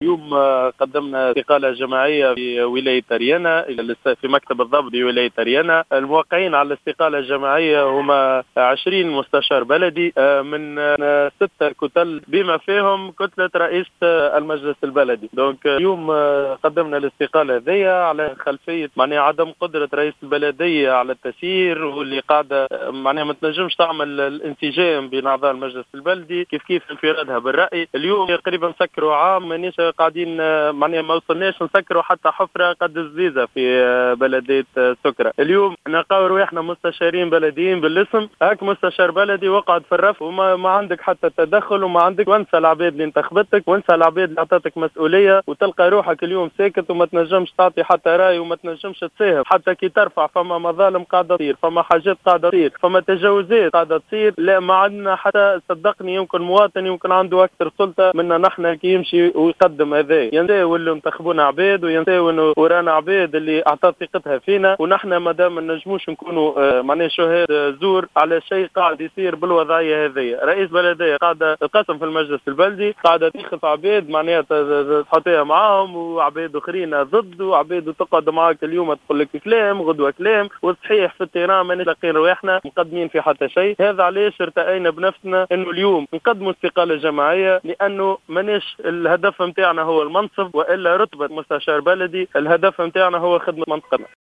و أوضح أيمن بن صالح أحد الأعضاء المستقيلين في تصريح للجوهرة "اف ام" اليوم أن هذه الإستقالة جاءت على خلفية عجزهم عن احداث أية اصلاحات في المنطقة التي انتخبهم سكانها مؤكدا أن سياسة الإنفراد بالرأي هي التي تغلبت على المصلحة العامة داخل البلدية.